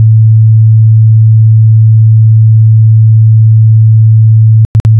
Ich habe die Sonifikation dieses Zustandsübergangs unten eingebettet.
Die 55-Hz-Sinuswelle ist der Grundzustand.
Die Aussetzer sind das Zucken.
Der Rauschboden steigt mit der Entropie.